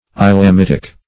islamitic - definition of islamitic - synonyms, pronunciation, spelling from Free Dictionary
Islamitic \Is`lam*it"ic\, a.